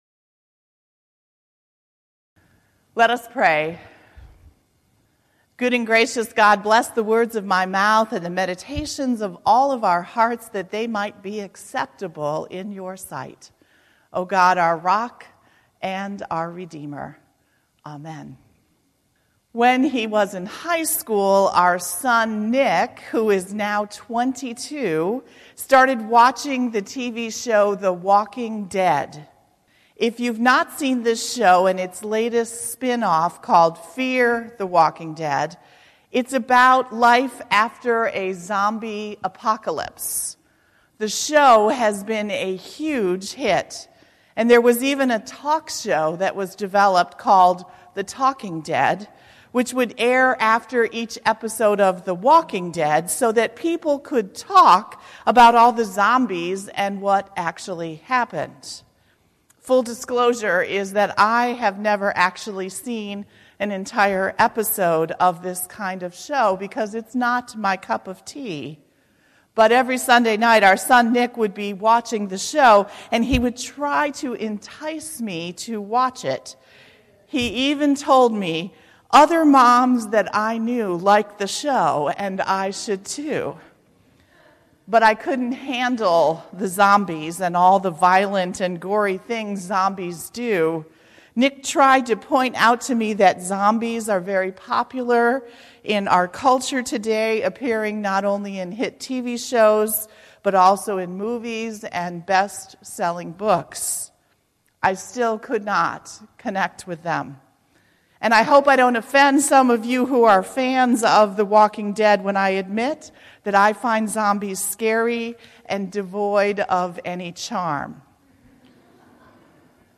April1518-Sermon-1.mp3